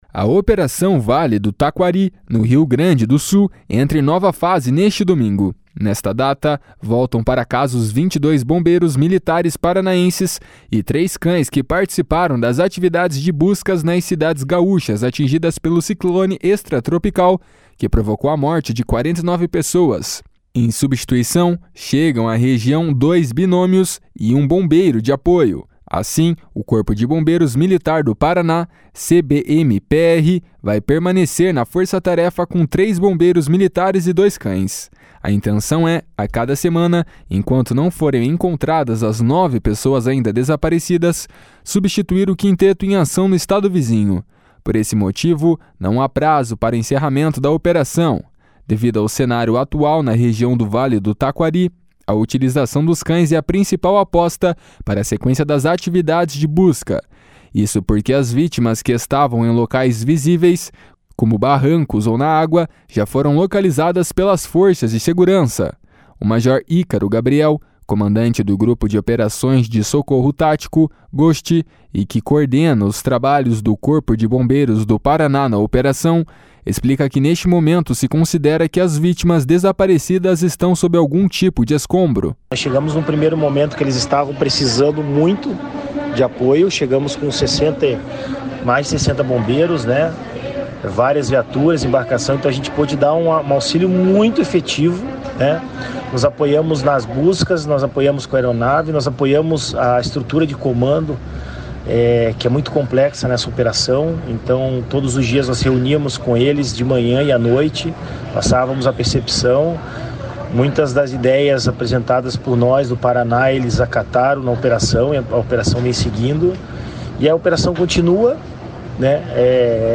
Bombeiro paranaense relata experiência no RS e reforça que operação é extremamente difícil
BOMBEIRO PARANAENSE RELATA EXPERIENCIA NO RS.mp3